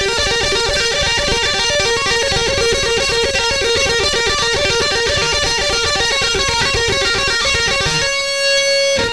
Il s'agit d'un exercice basé le tapping. Cette technique consiste à frapper des notes sur le manche avec la main droite (ou la main gauche pour les gauchers).